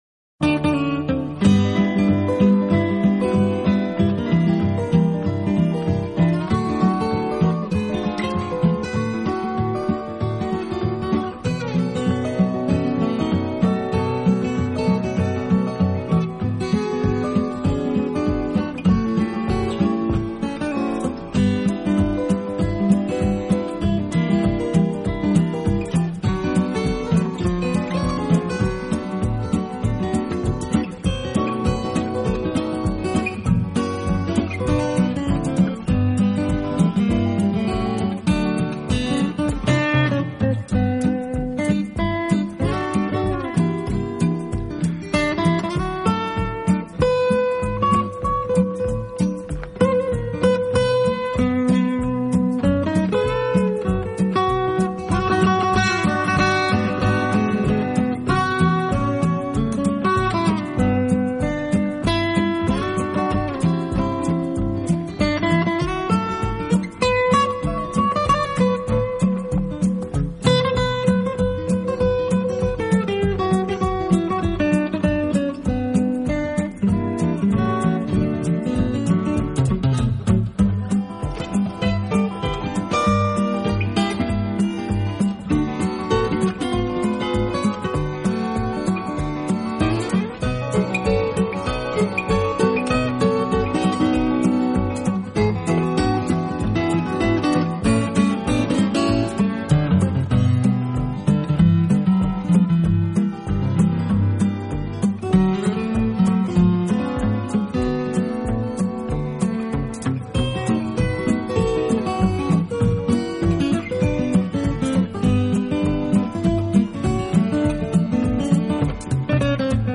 音乐类型: Jazz